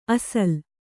♪ asal